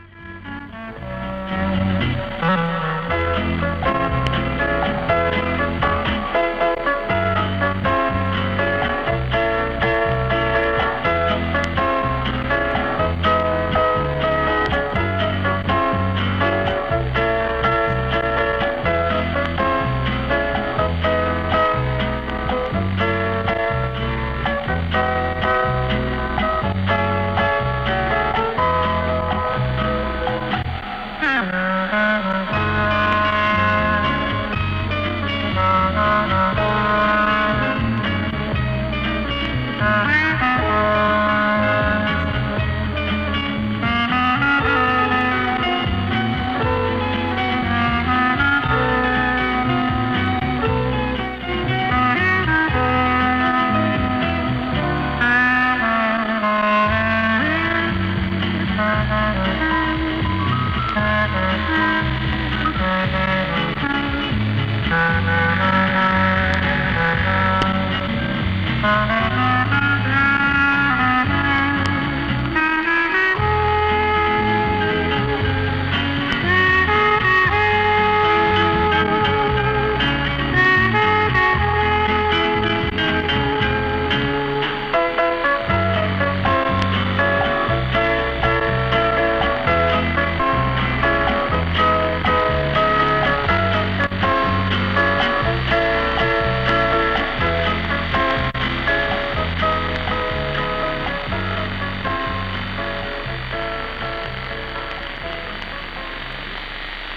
югославский кларнетист и клавишник